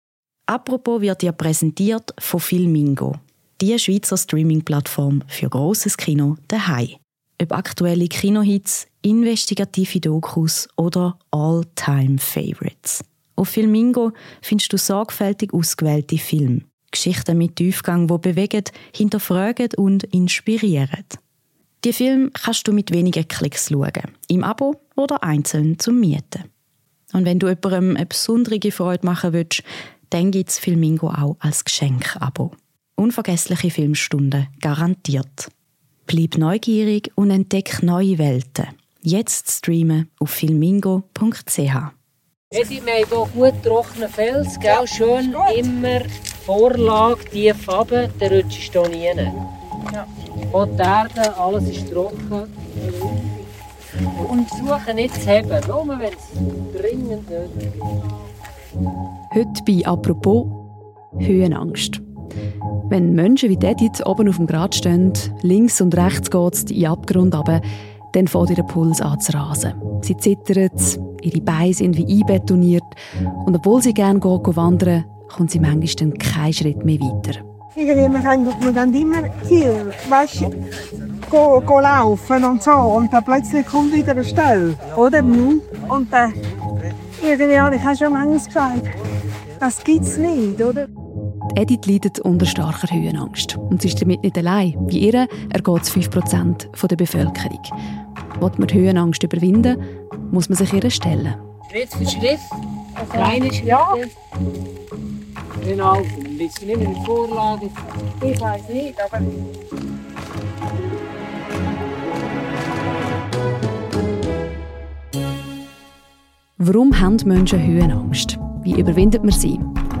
Eine Reportage vom Berg.